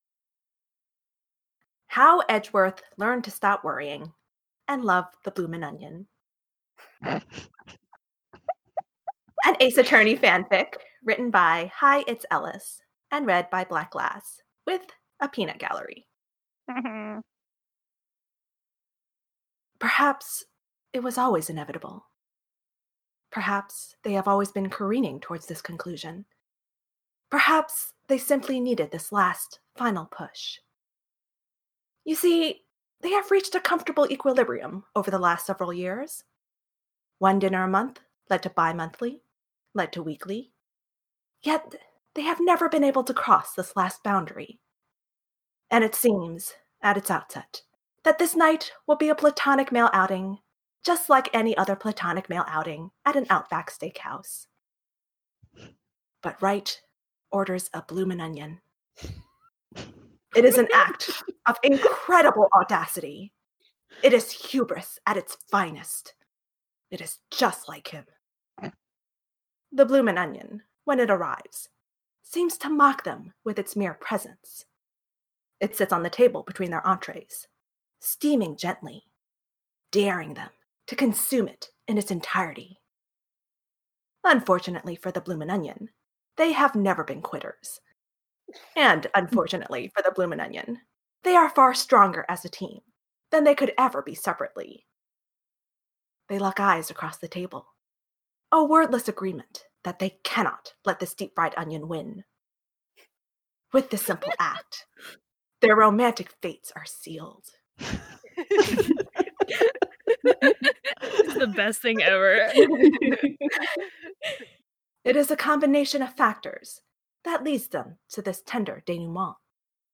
reader